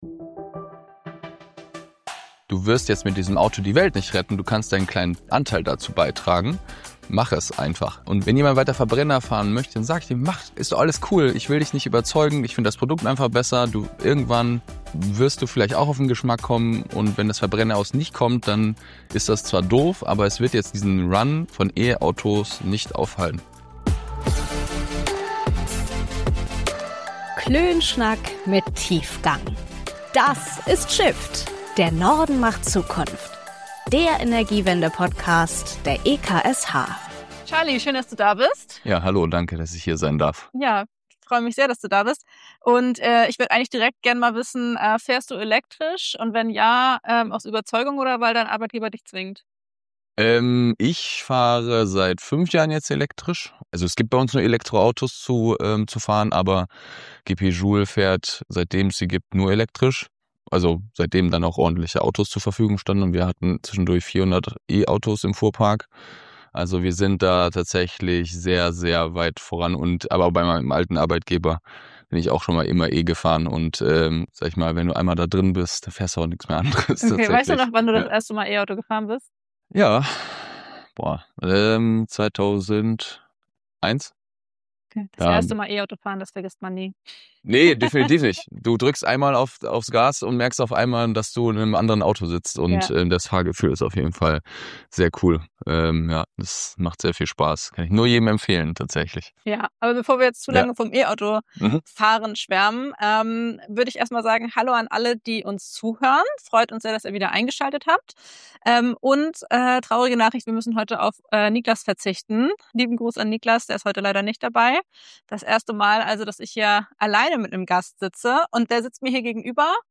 Wie gut ist die Ladeinfrastruktur für Elektroautos in Deutschland? Viel Spaß bei unserem Klönschnack mit Tiefgang!